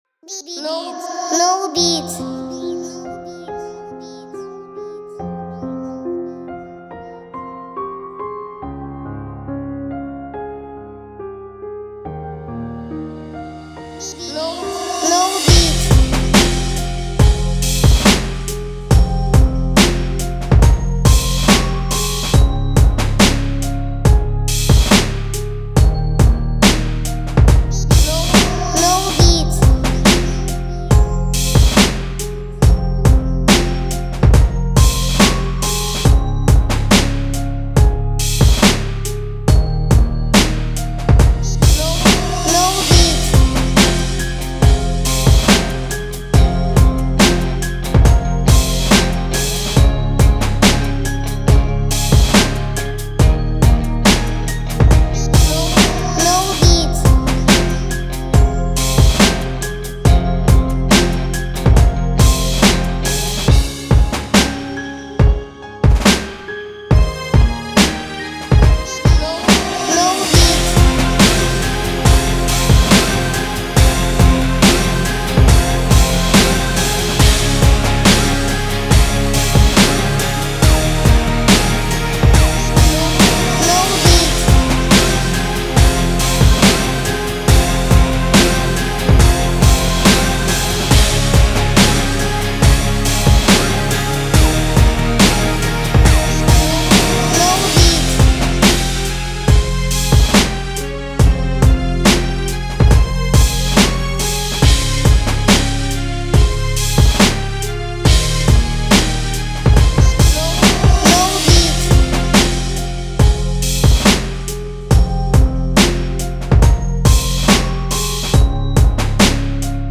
WOUNDS_-140_bpm